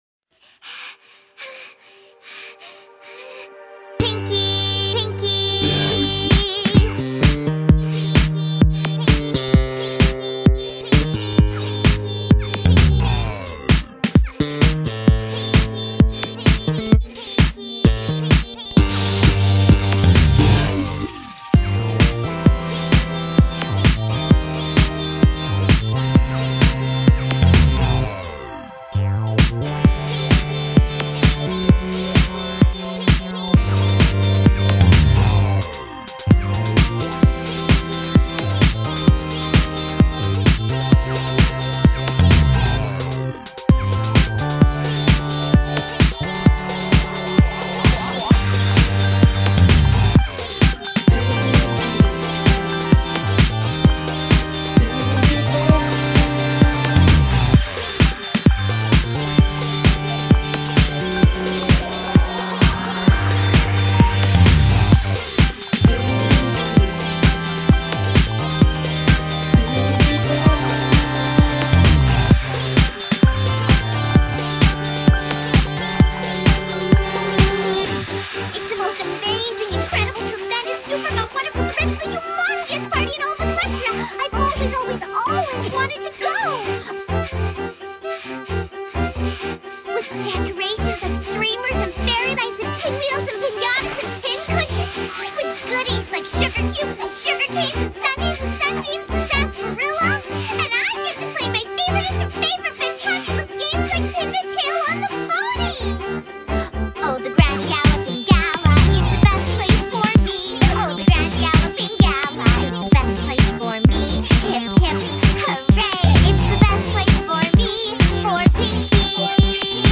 Funky!